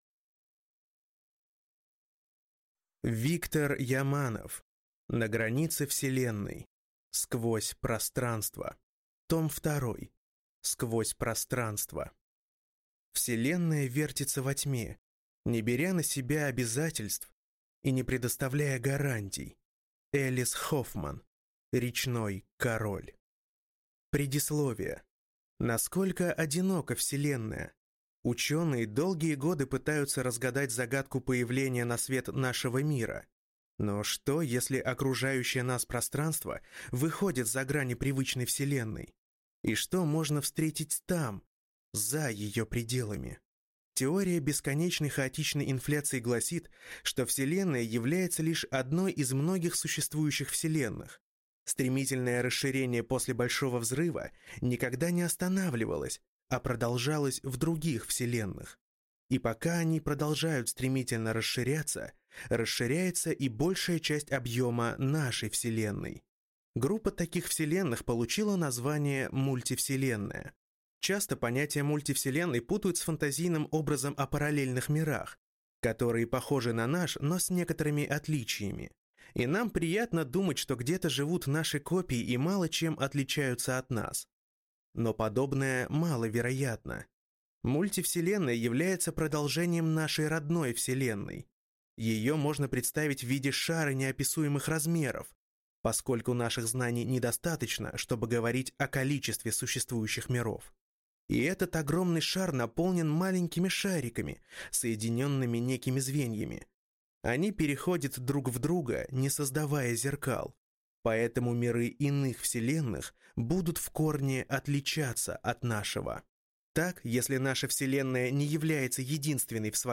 Аудиокнига На границе Вселенной. Сквозь пространство | Библиотека аудиокниг